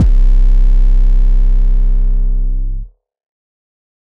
• 50 high-quality, hard-hitting 808 drum samples.
• A variety of tones from clean subs to gritty distortion.